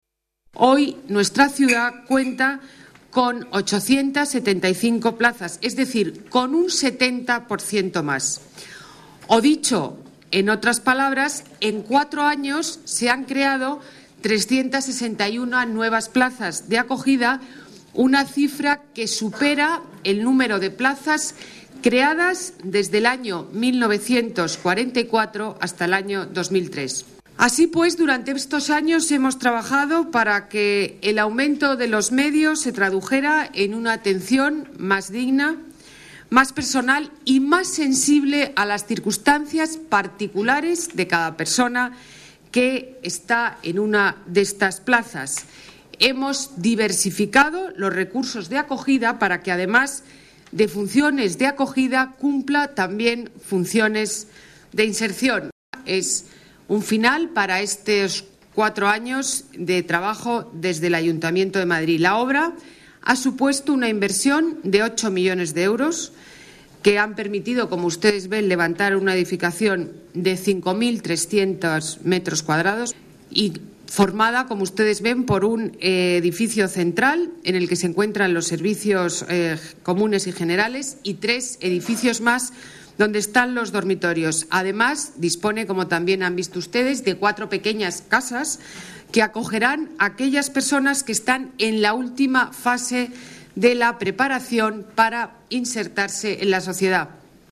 Nueva ventana:Declaraciones de Ana Botella durante la inauguración del nuevo Centro de Acogida "Juan Luis Vives"